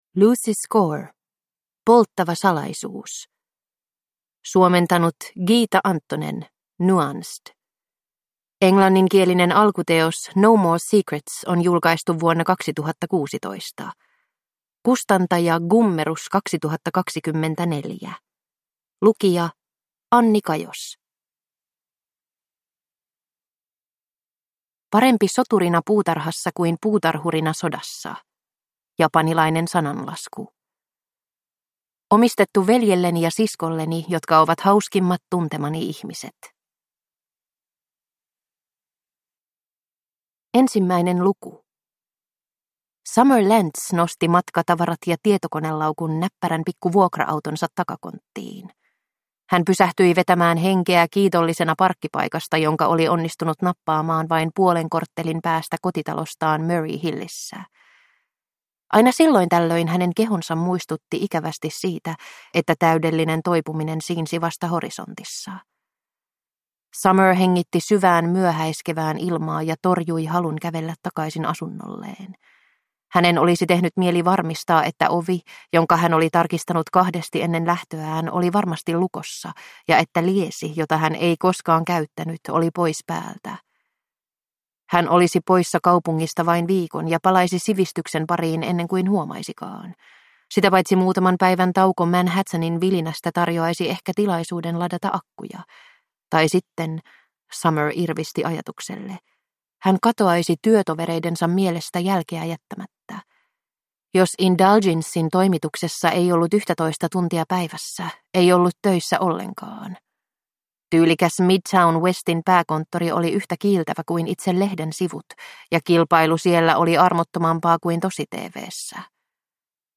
Polttava salaisuus (ljudbok) av Lucy Score